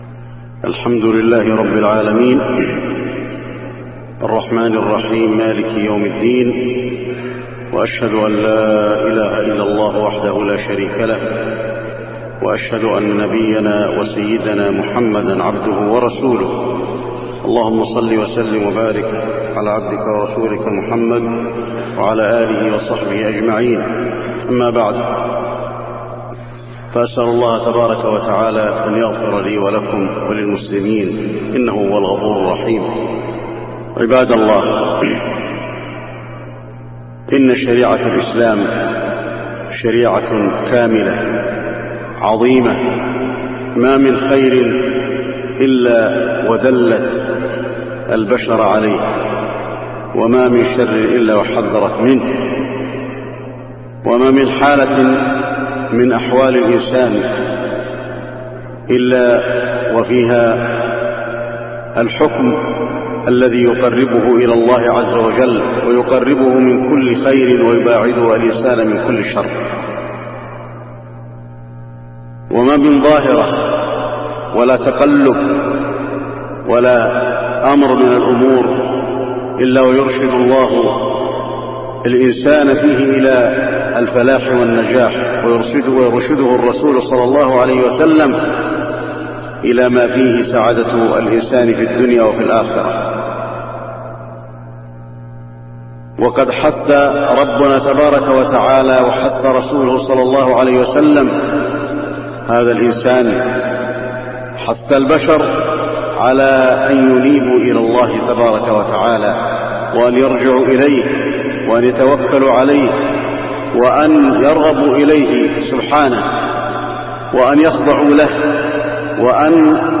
خطبة الخسوف المدينة - الشيخ علي الحذيفي
تاريخ النشر ١٤ صفر ١٤٢٨ هـ المكان: المسجد النبوي الشيخ: فضيلة الشيخ د. علي بن عبدالرحمن الحذيفي فضيلة الشيخ د. علي بن عبدالرحمن الحذيفي خطبة الخسوف المدينة - الشيخ علي الحذيفي